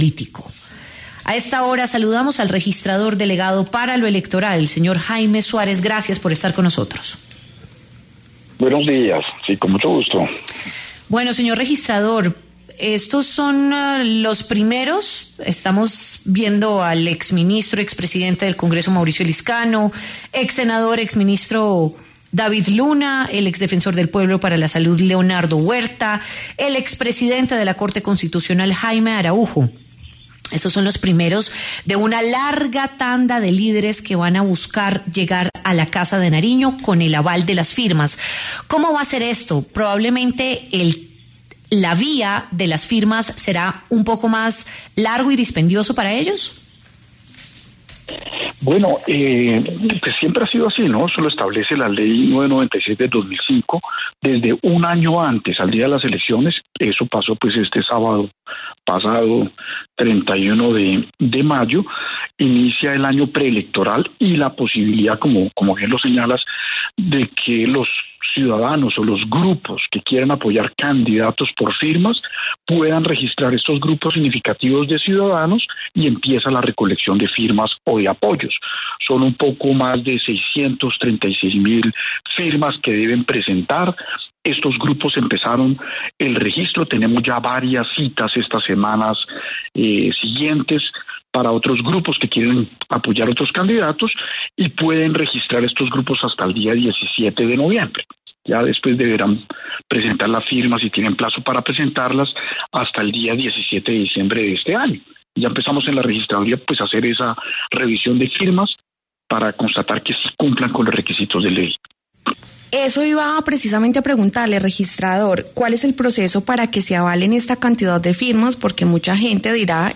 Jaime Suárez, registrador delegado para lo electoral, explicó en La W los tiempos de inscripción de candidaturas, cuántas firmas necesitan los movimientos y más procesos de esta carrera presidencial.